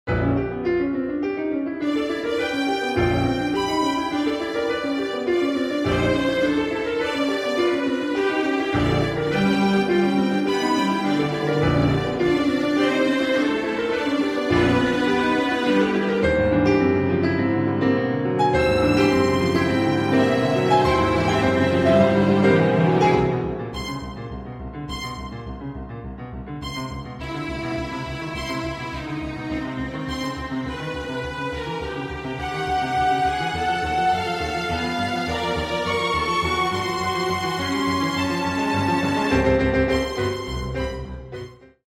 toccata für klavier und streicher
allegro-largo-allegro